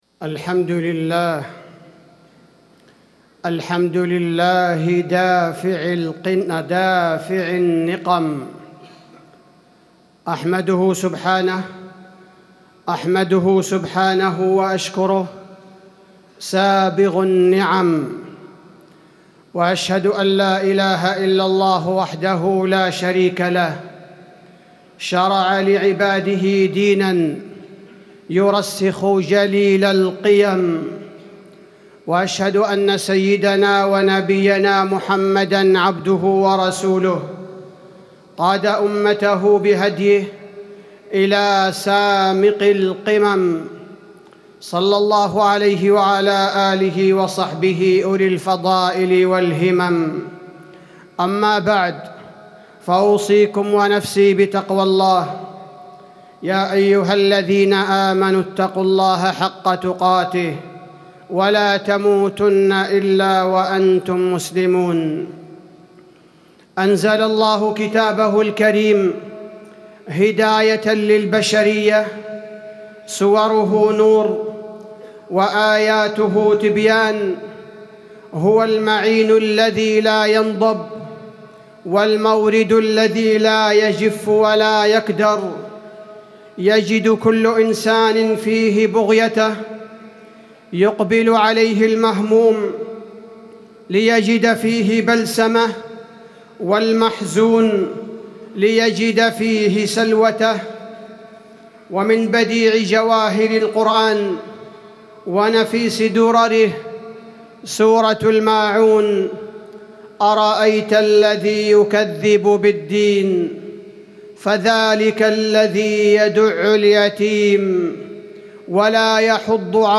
تاريخ النشر ١٨ شعبان ١٤٣٩ هـ المكان: المسجد النبوي الشيخ: فضيلة الشيخ عبدالباري الثبيتي فضيلة الشيخ عبدالباري الثبيتي تأملات في سورة الماعون